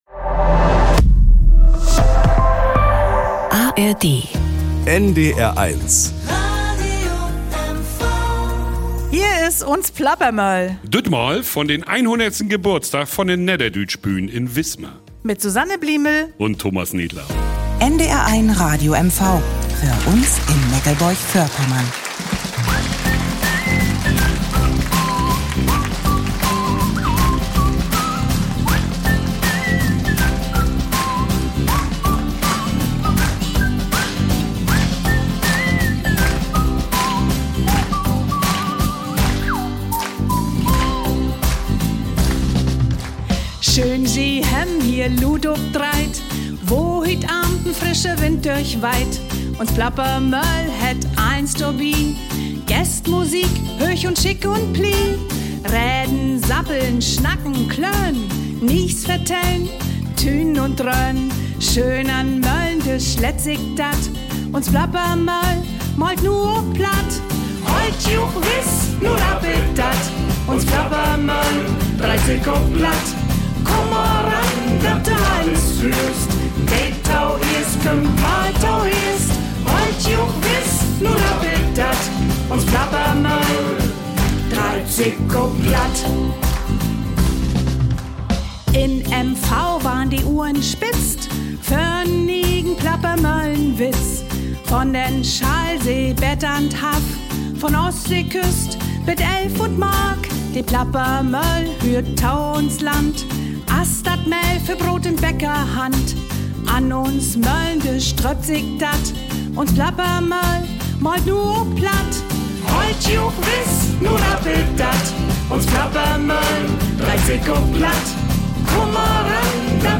Im Zeughaus ist die Sendung zum Jubiläum aufgezeichnet worden - der Saal voll, die Leute fröhlich.